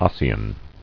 [os·se·in]